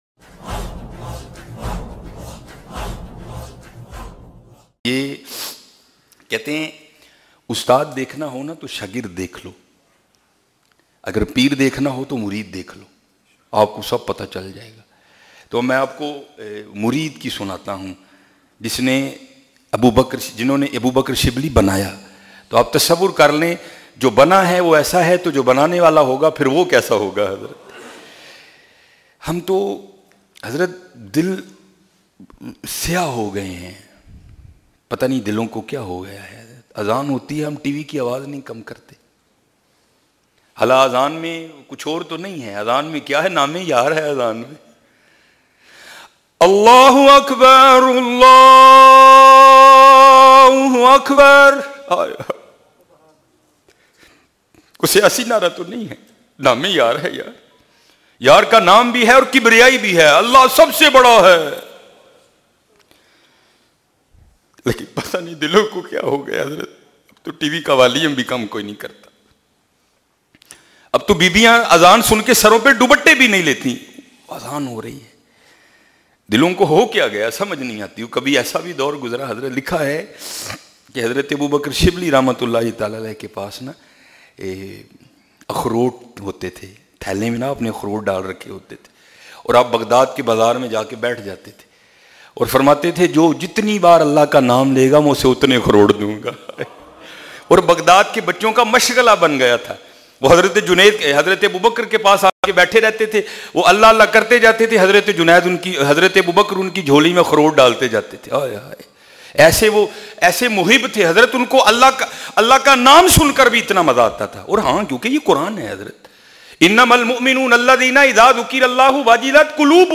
Ab tu Azan ky waqat tv ki awaz bayan mp3